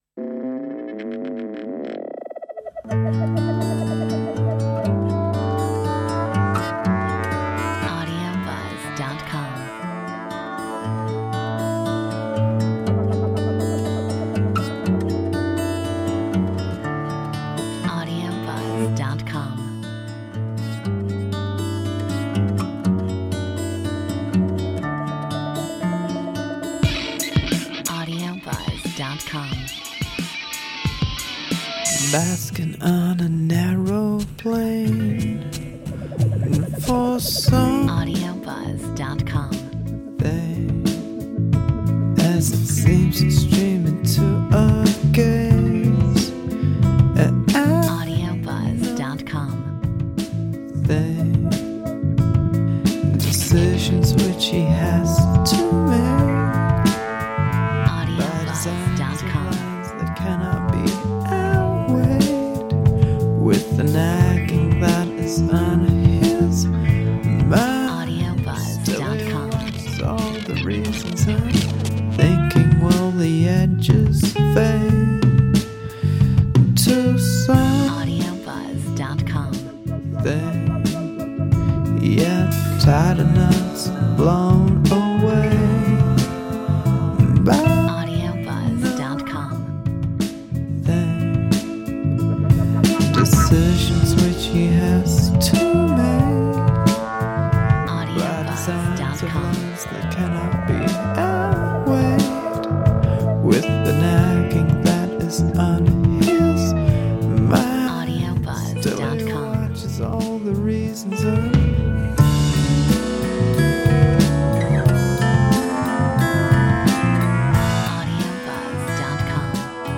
Metronome 90